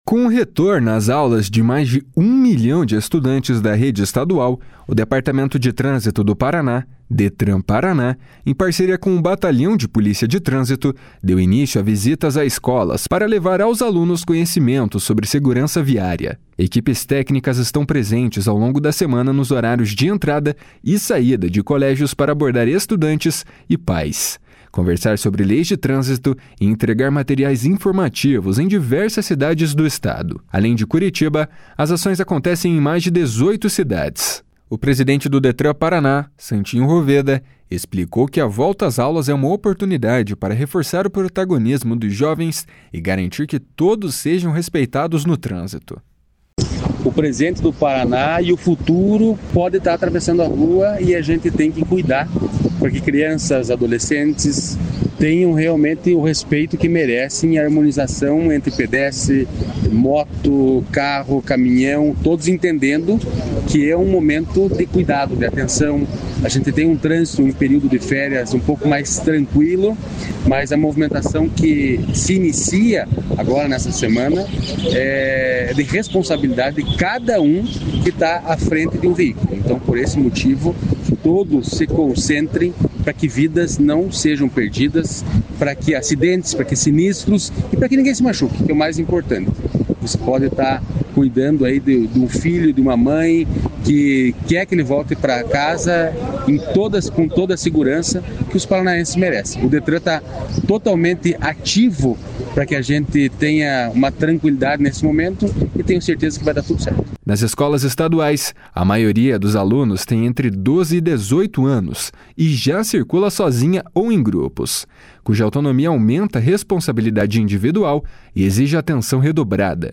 O presidente do Detran-PR, Santin Roveda, explicou que a volta às aulas é uma oportunidade para reforçar o protagonismo dos jovens e garantir que todos sejam respeitados no trânsito. // SONORA SANTIN ROVEDA //